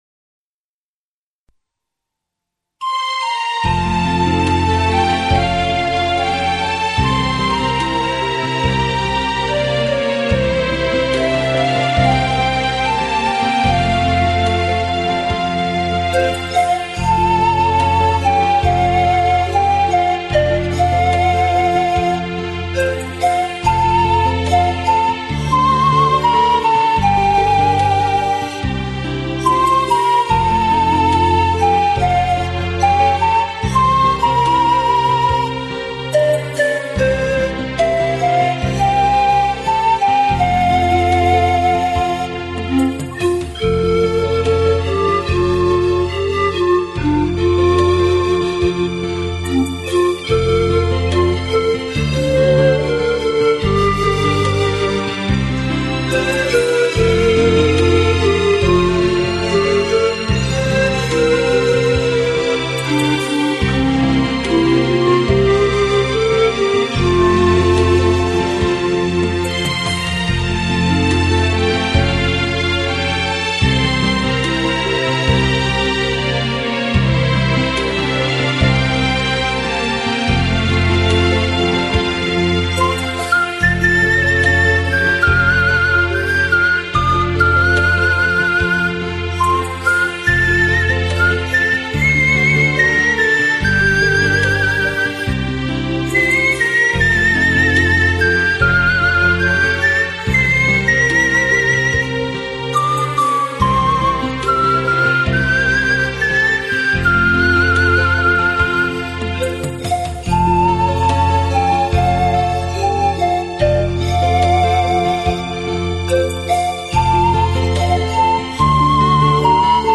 由排箫来主奏
排箫的音色质朴、悠扬， 最大的长处是抒情性强，久听不腻。
专辑中乐曲的配器较平 和简易，最后一曲“圣母颂”用了两只排箫，听起来音色非常丰满。